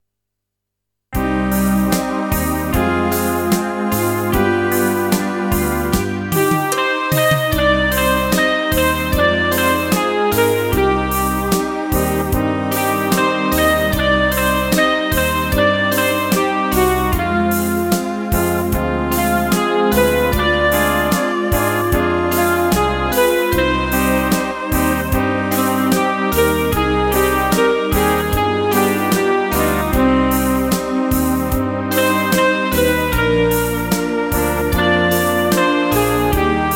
kytara
Rubrika: Pop, rock, beat